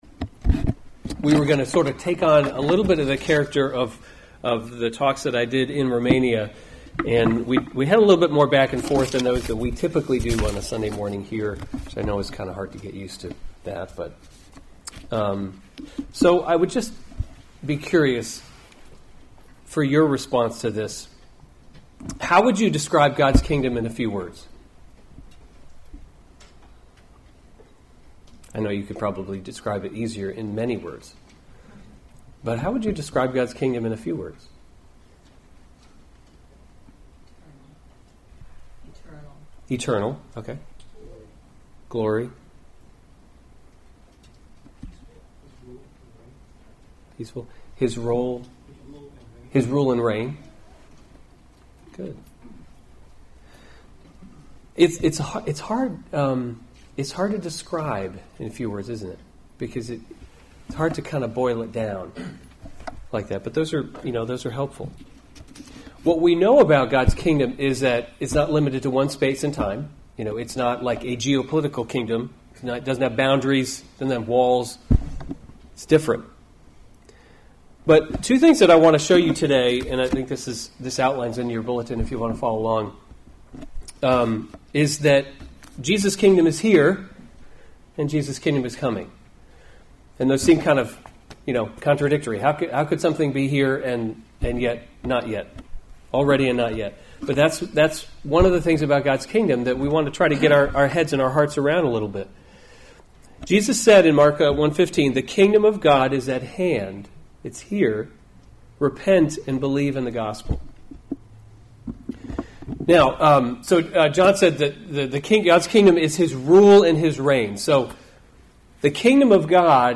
July 8, 2017 Lord’s Prayer: Kingdom Come series Weekly Sunday Service Save/Download this sermon Matthew 6:10a Other sermons from Matthew 10 Your kingdom come, your will be done, [1] on earth as […]